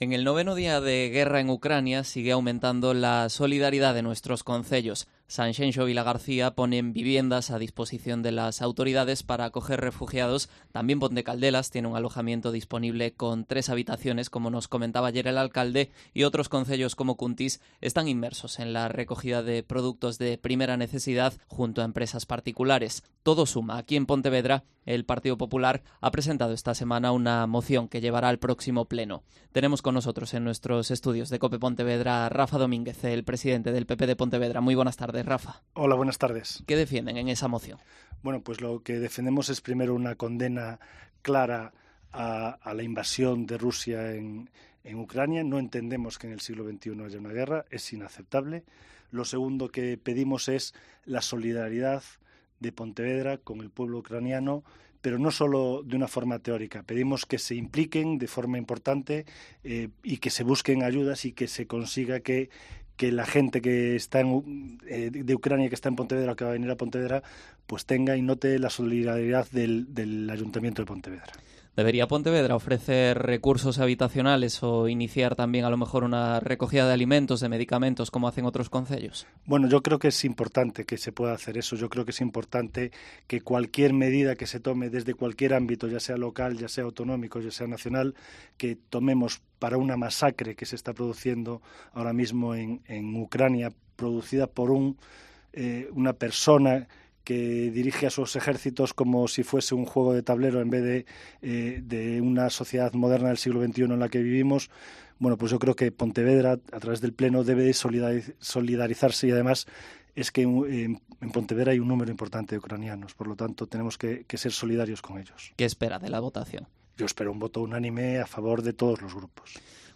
Entrevista a Rafa Domínguez, presidente del PP de Pontevedra